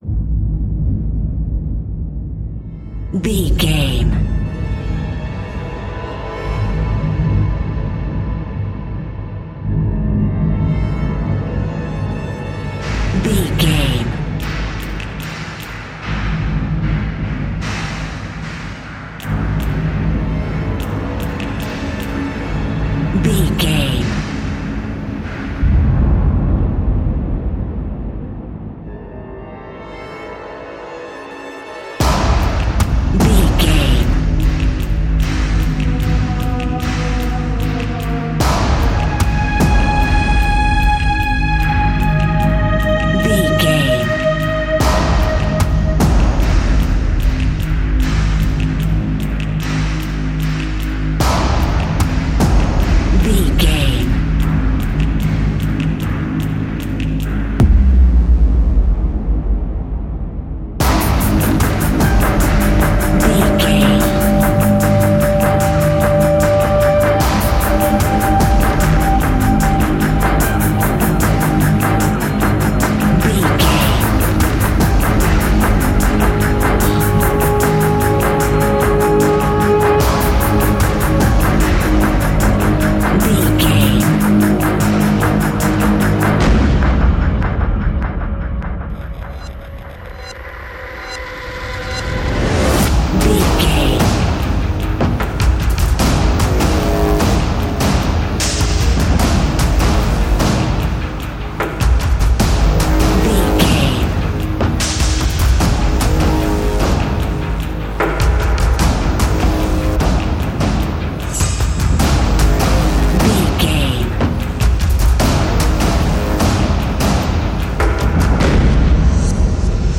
Epic / Action
Fast paced
Ionian/Major
G♭
dark ambient
EBM
synths